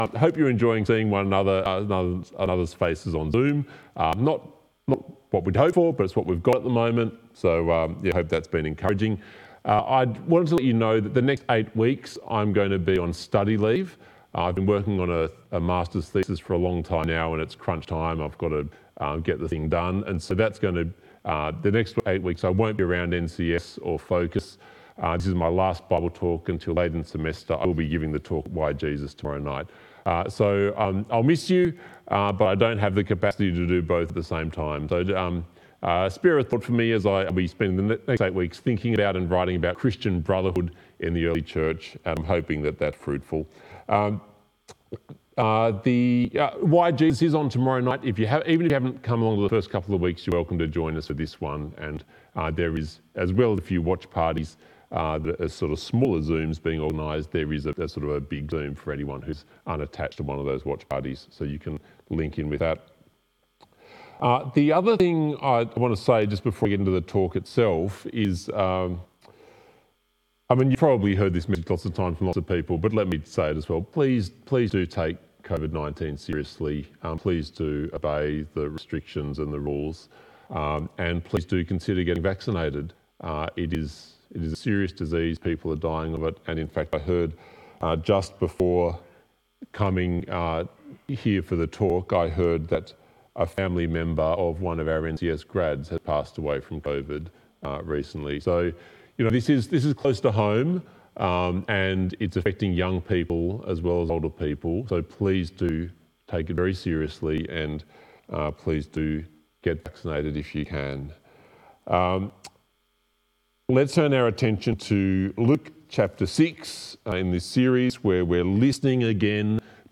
Passage: Luke 6:39-49 Talk Type: Bible Talk